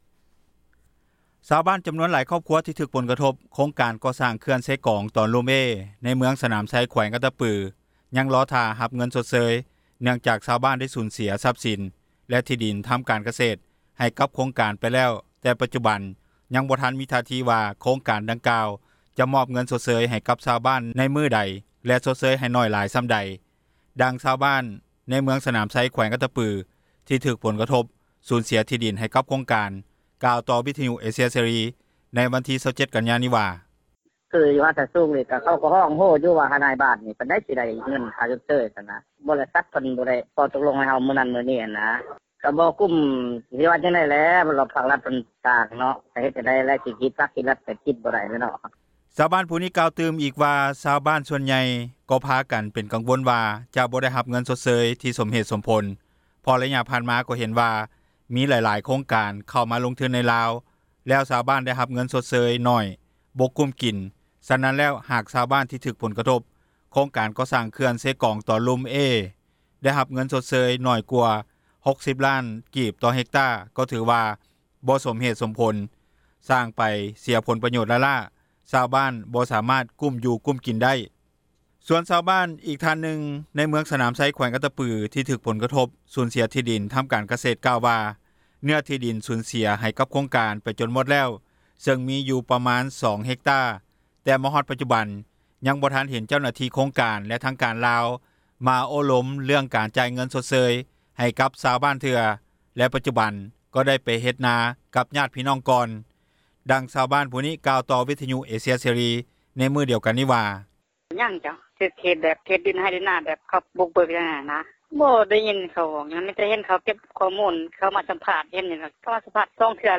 ດັ່ງຊາວບ້ານ ຜູ້ນີ້ກ່າວຕໍ່ວິທຍຸ ເອເຊັຽເສຣີ ໃນມື້ດຽວກັນນີ້ວ່າ:
ດັ່ງເຈົ້າໜ້າທີ່ ແຂວງອັດຕະປືກ່າວຕໍ່ ວິທຍຸເອ ເຊັຽເສຣີ ໃນມື້ດຽວກັນນີ້ວ່າ: